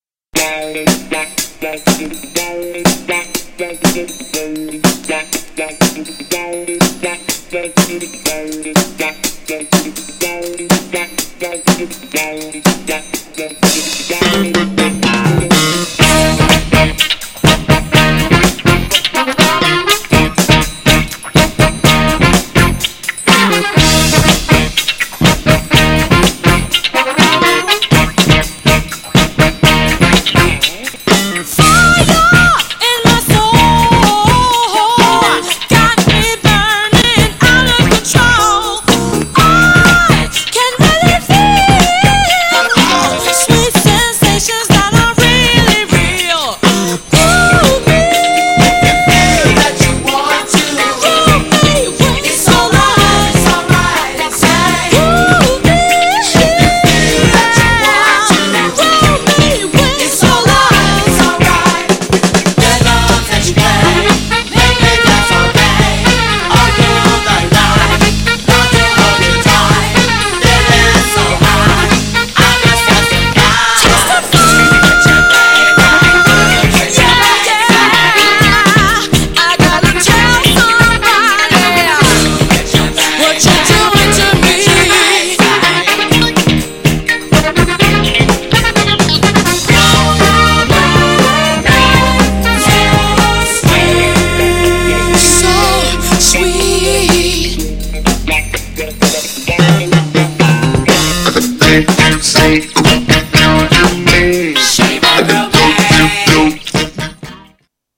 アッパーなFUNKY DISCOからスローまで何でもこなす彼ら。
GENRE Dance Classic
BPM 76〜80BPM